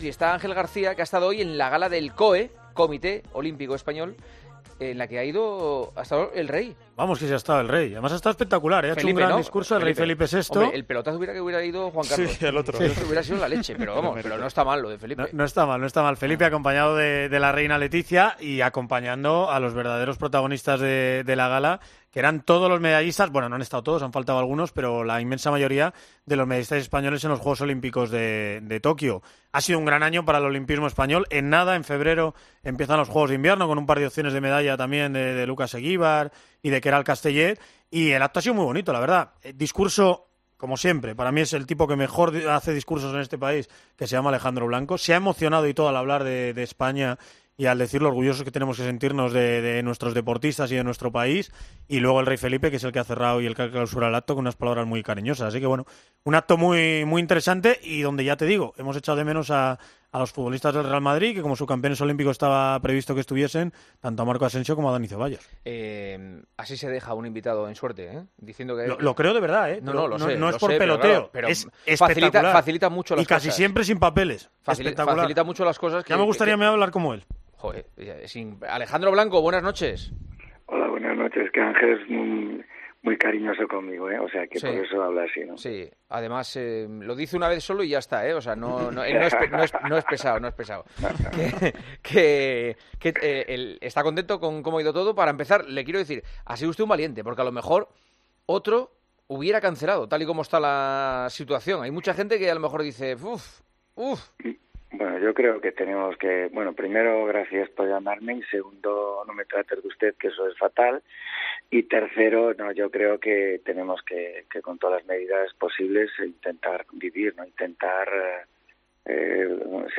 ¿Serán el frisbee, el póker o el pádel futuros deportes olímpicos? El debate quedó abierto en la charla que el equipo de El Partidazo de COPE mantuvo este miércoles con el presidente del COE , Alejandro Blanco .